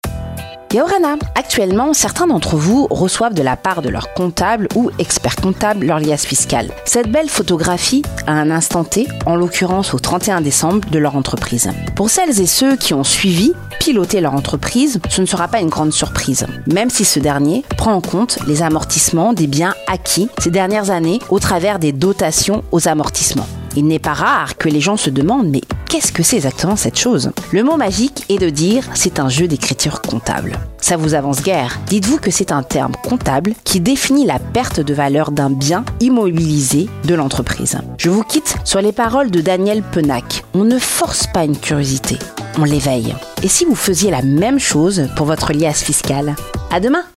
LA MINUTE DE L’ENTREPRENEUR, votre nouvelle rubrique sur l’entrepreneuriat à 6h40, 12h40 et 16h40 sur Radio1.